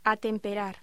Locución: Atemperar